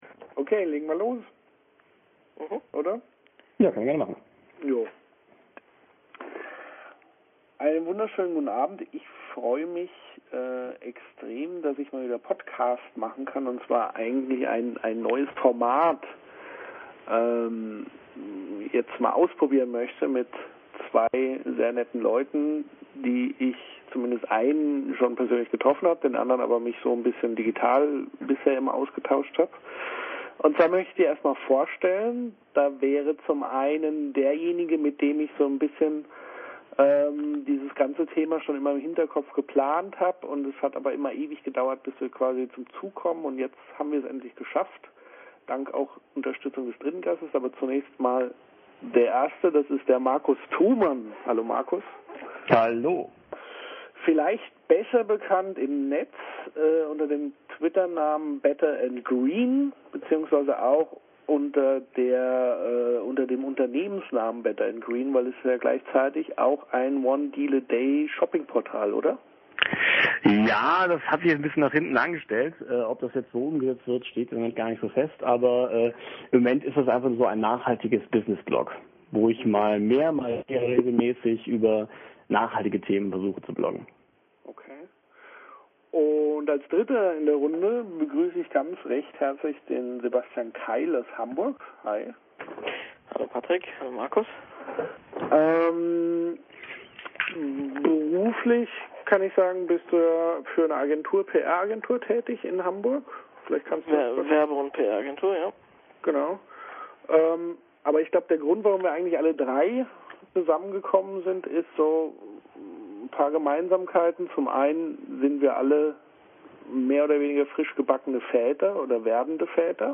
Zugegeben, die Qualität ist nicht gerade berauschend, sondern rauschend, aber Spass gemacht hat’s trotzdem.